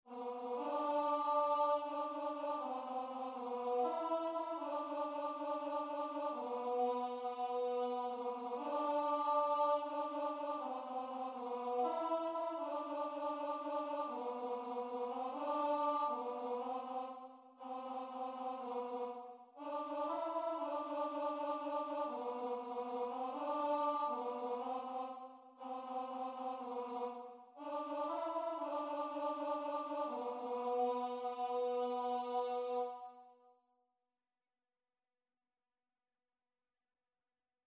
Free Sheet music for Choir (SATB)
Joyfully = c.120
G major (Sounding Pitch) (View more G major Music for Choir )